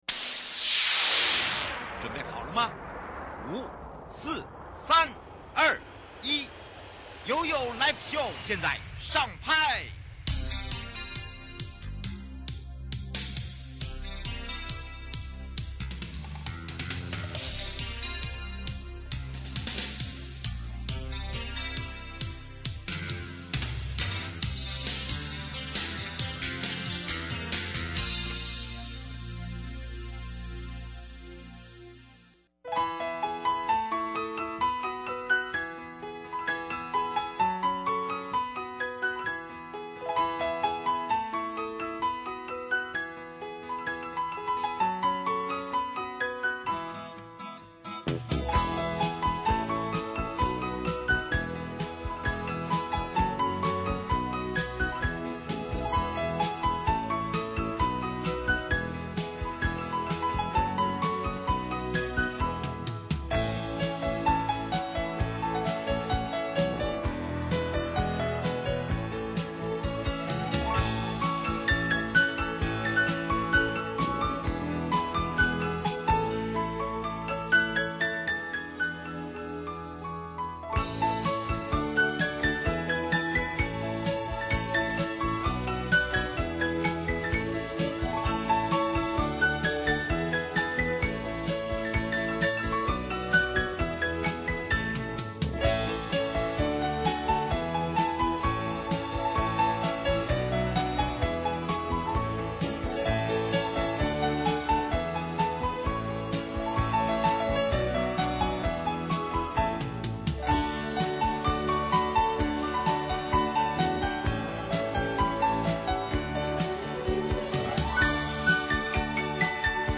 受訪者： 台灣高等檢察署 節目內容： 跟蹤騷擾防制法簡介 為何要制定跟蹤騷擾防制法?